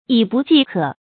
以不济可 yǐ bù jì kě
成语注音ㄧˇ ㄅㄨˋ ㄐㄧˋ ㄎㄜˇ